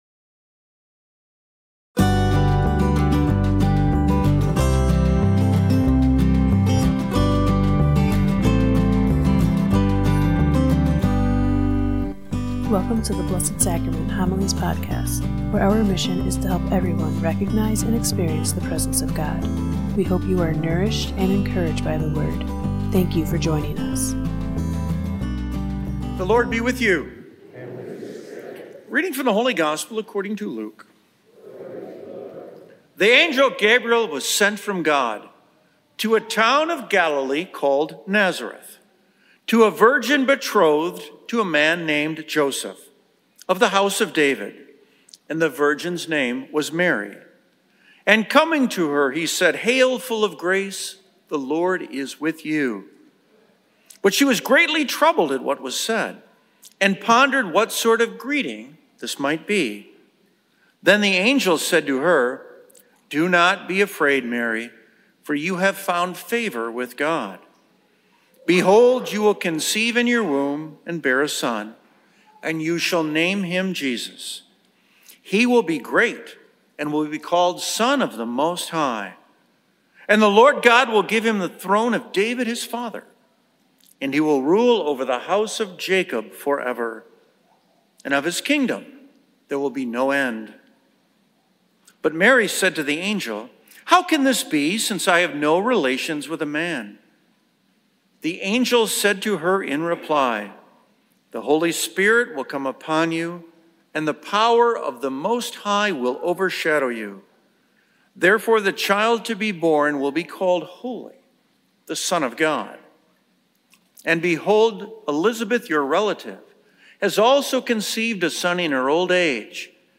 Blessed Sacrament Parish Community Homilies